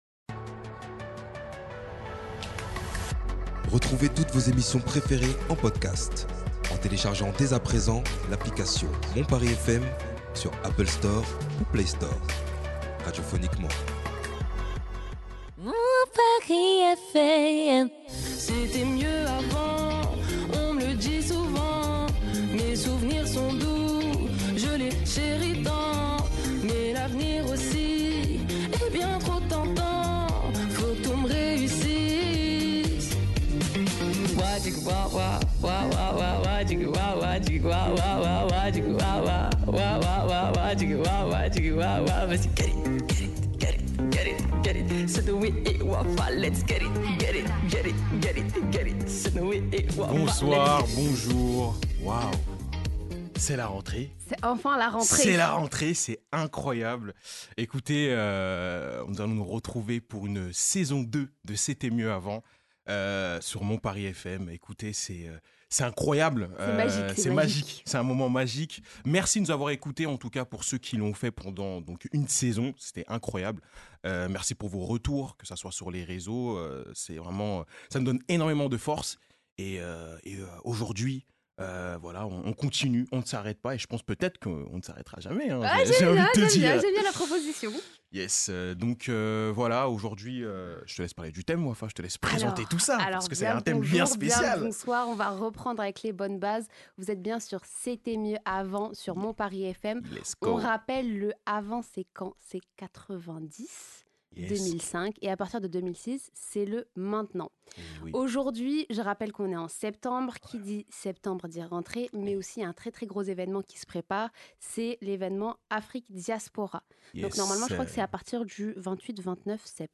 Pour cette émission, nous reviendrons sur l'aspect historique des diasporas avant d'écouter l'avis de nos chroniqueurs avant d’enchaîner sur des questions plus techniques ! Les arguments et statistiques seront de mise pour prouver si oui ou non c’était mieux avant. Nous voulons savoir si la nostalgie s’allie aux faits pour attester que c’était mieux avant ou si les nouveaux générations sont plus impliquées que les anciennes.